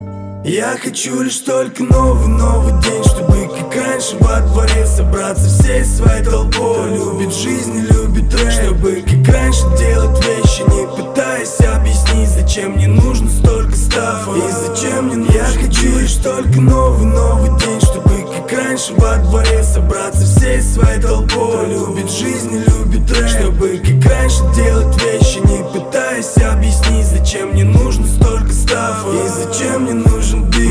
душевные
русский рэп
ностальгия